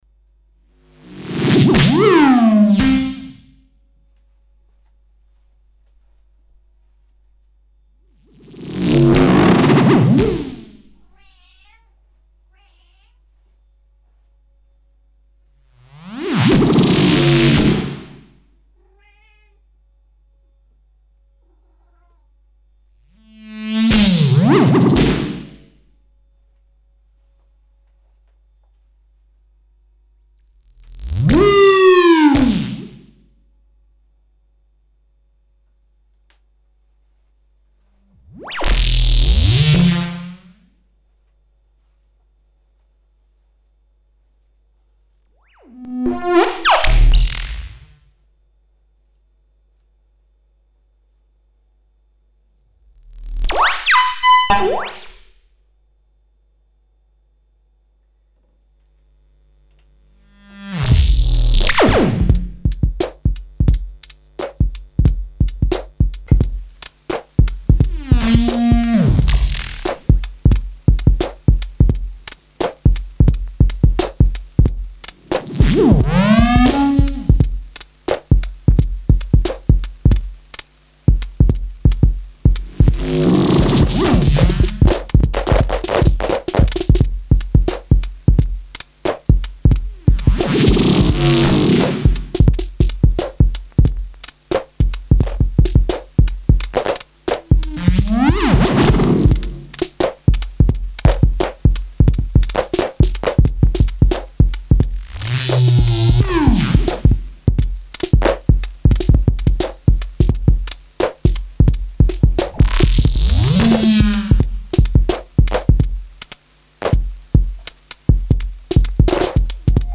Indirectly, here are some fart noises I made on a Roland Jupiter 6 with CV mods, using a Moog Mother-32 to control it.